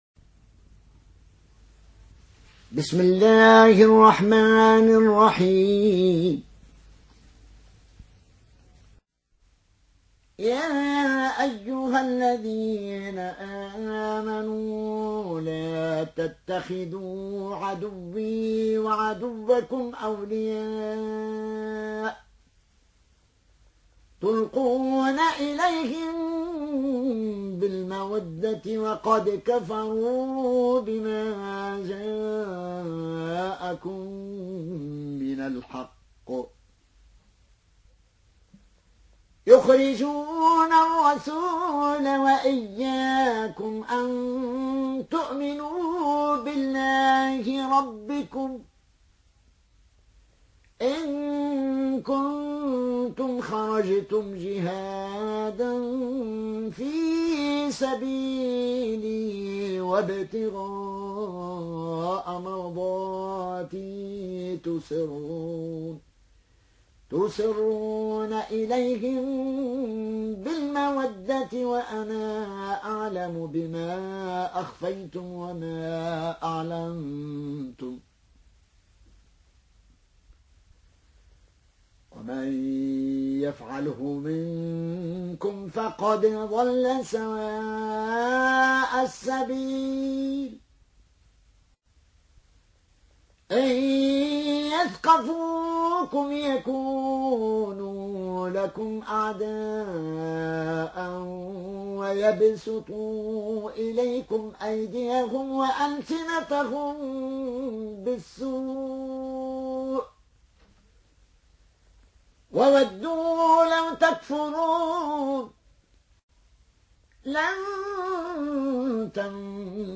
Riwayat Qaloon an Nafi